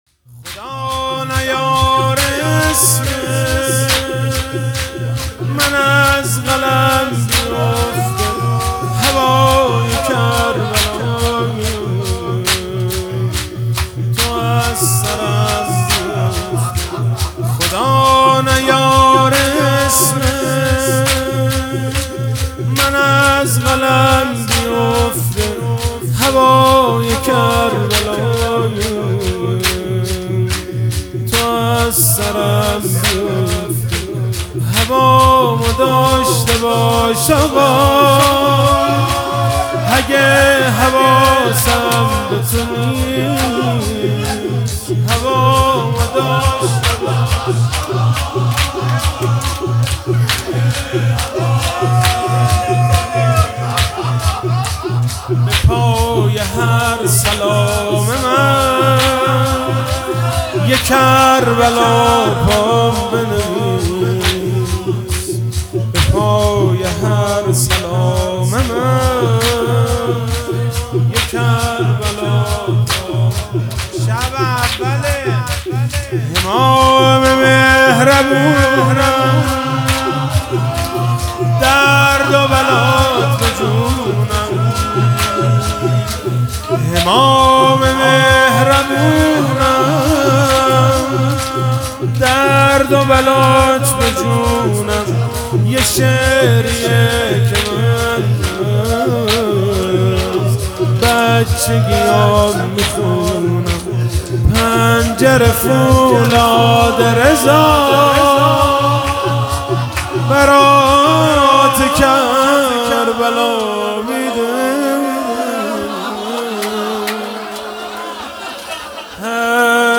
شب اول فاطمیه1400(به روایت75روز)